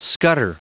Prononciation du mot scutter en anglais (fichier audio)
Prononciation du mot : scutter